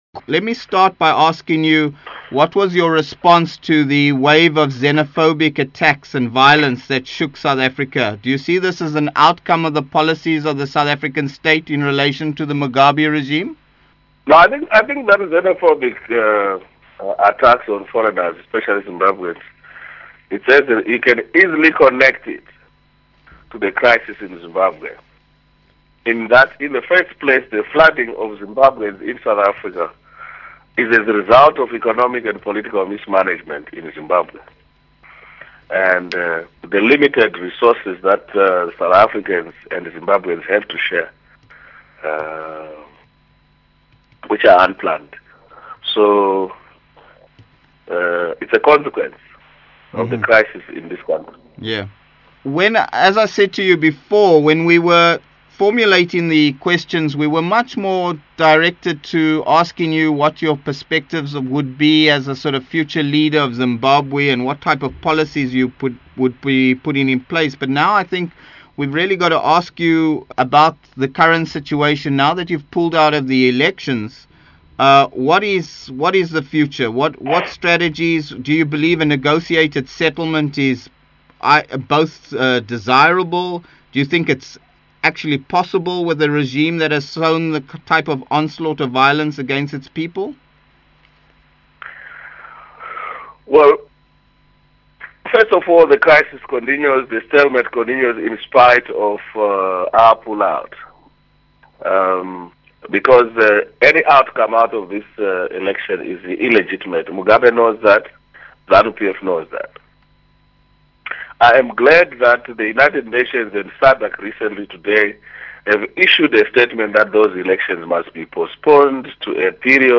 Kubatana - Archive - Amandla! Interviews Morgan Tsvangirai - Amandla! - Jun 25, 2008